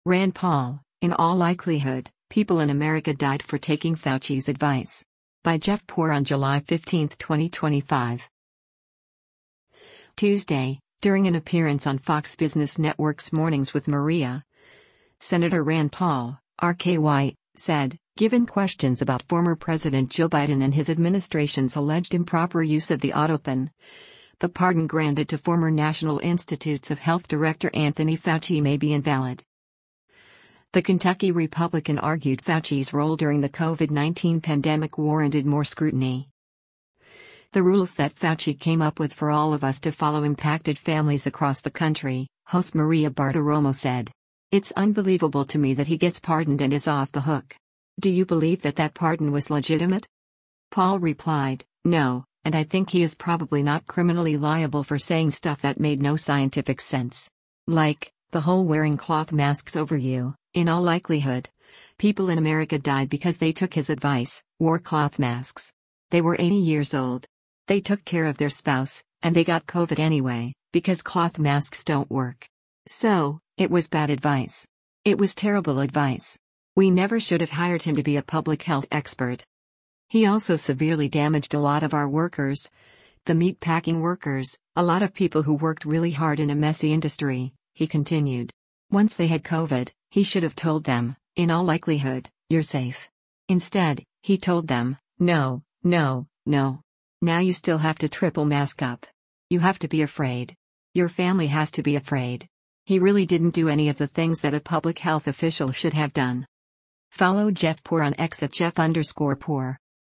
Tuesday, during an appearance on Fox Business Network’s “Mornings with Maria,” Sen. Rand Paul (R-KY) said, given questions about former President Joe Biden and his administration’s alleged improper use of the autopen, the pardon granted to former National Institutes of Health director Anthony Fauci may be invalid.